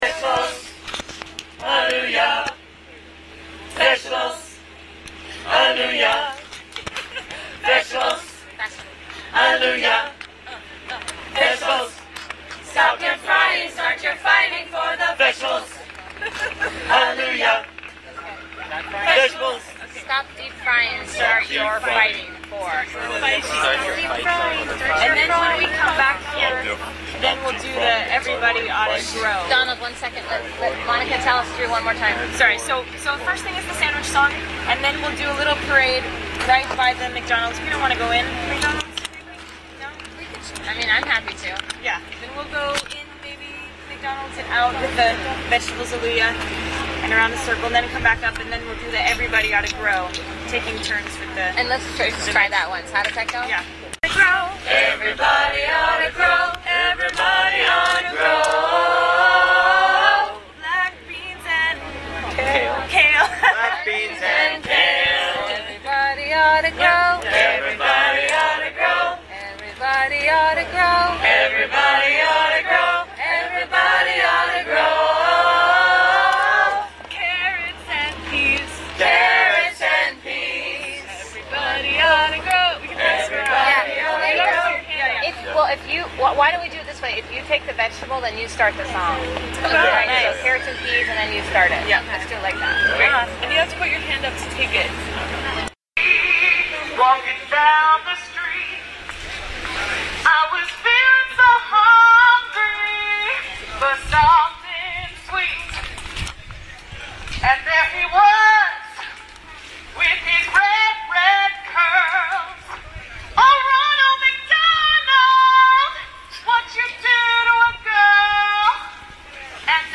outside the McDonald's, St Peters Gate
4.00pm Thursday 14th July 2011 outside the McDonald's, St Peters Gate There was a free food give-away in Nottingham City Centre, outside the McDonald's at St Peters Gate. Free vegan food samples was given out to passers by, along with information and recipe books compiled by Nottingham Vegan Campaigns.
gospel chunes